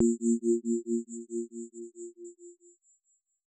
tone4.R.wav